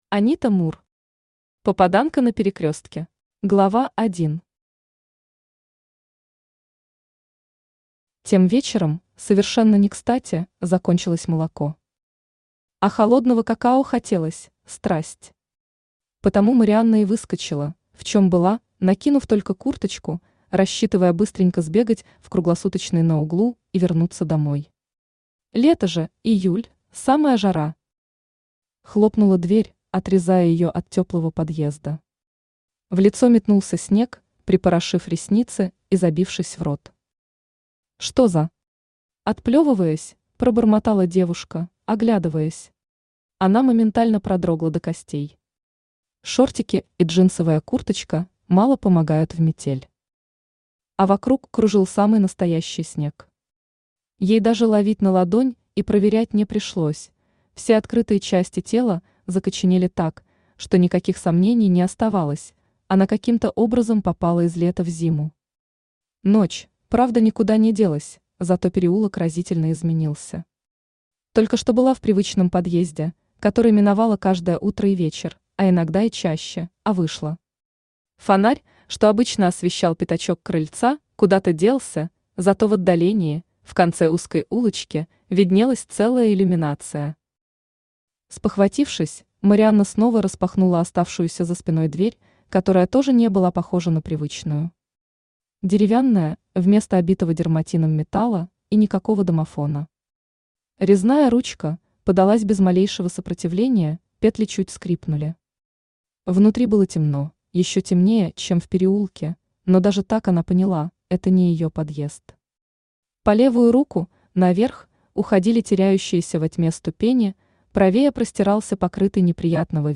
Аудиокнига Попаданка на Перекрёстке | Библиотека аудиокниг
Aудиокнига Попаданка на Перекрёстке Автор Анита Мур Читает аудиокнигу Авточтец ЛитРес.